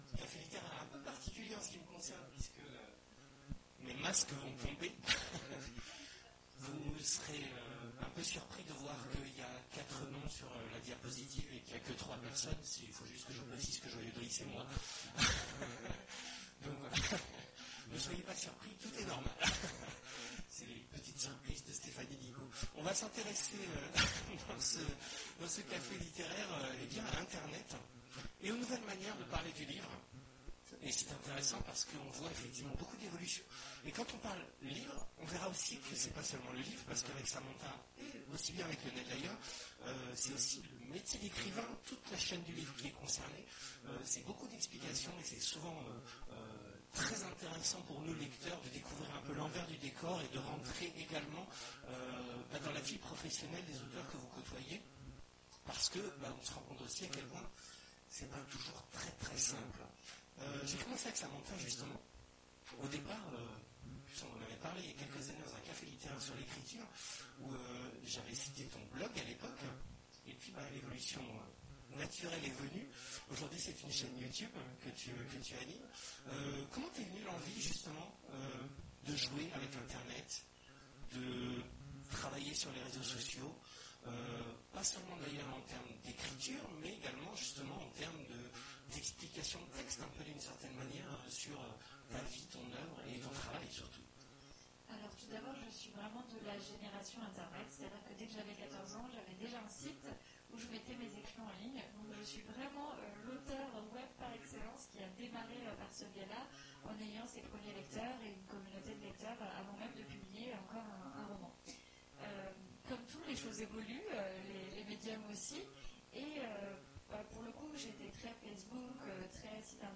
Mots-clés Edition Conférence Partager cet article